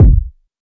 Kick (25).wav